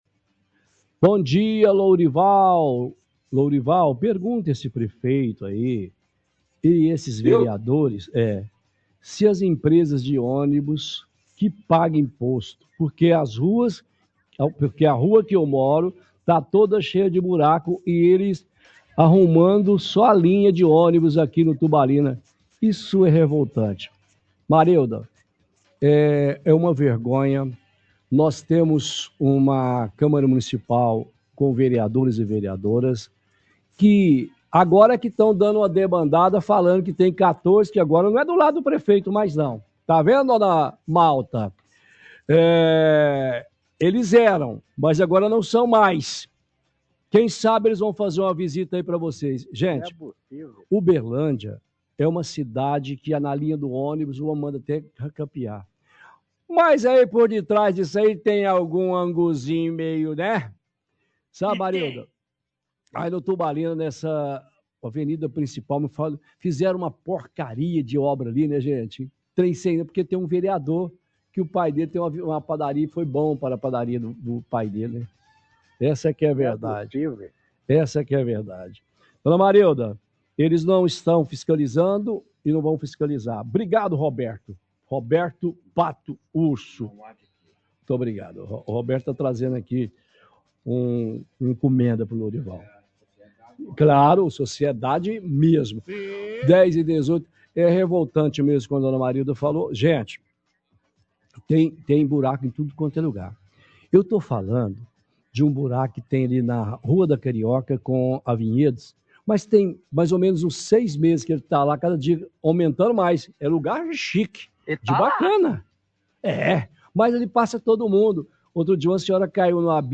– Utilização de áudios do vereador Abatênio para fazer deboches.